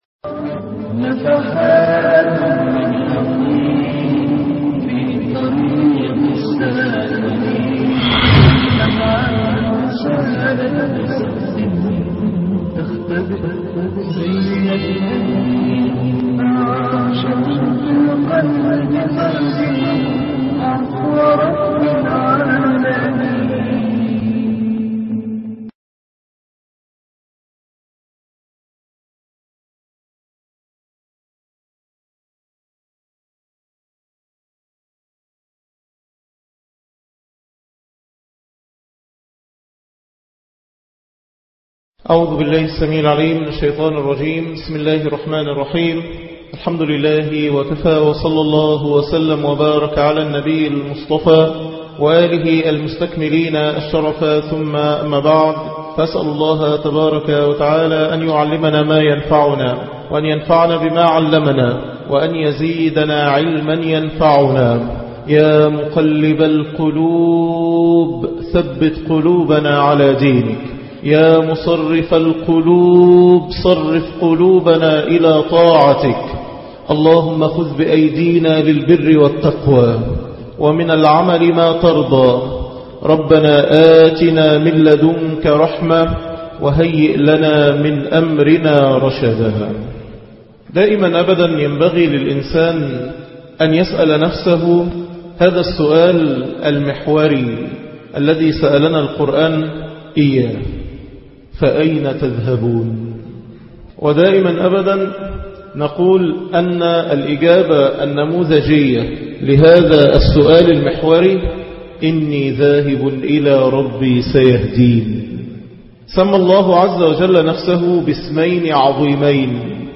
أخطر لحظة في حياتي- ندوات ودروس من المساجد